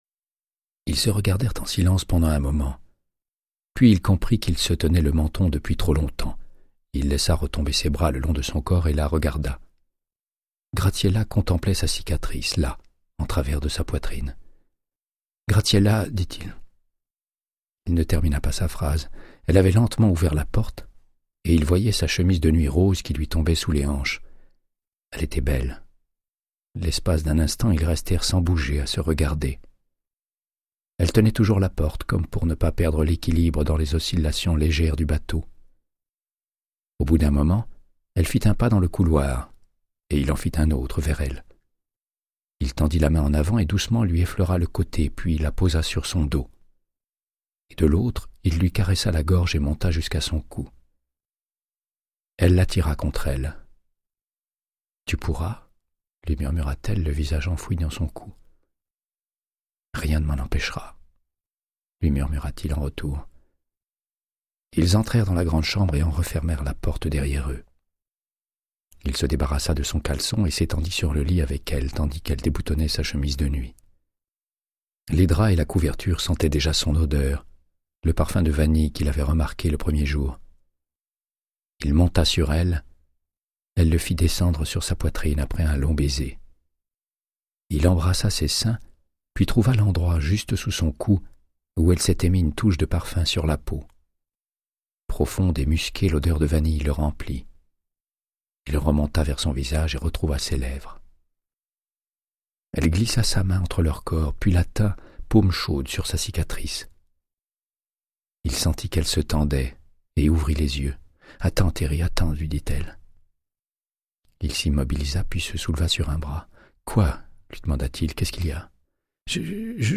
Audiobook = Créance de sang, de Michael Connellly - 93